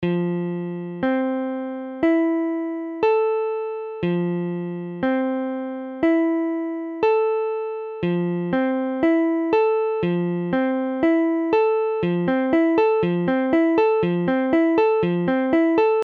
Tablature F7M.abcF7M : accord de Fa septième majeure
Mesure : 4/4
Tempo : 1/4=60
A la guitare, on réalise souvent les accords de quatre notes en plaçant la tierce à l'octave.
Fa septième majeure barré VIII (do case 8 fa case 8 do case 10 doigt 3 mi case 9 doigt 2 la case 10 doigt 4 do case 8)